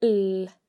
The non-slender L sounds much like the English ‘L’ sound, and can be heard in baile (a town):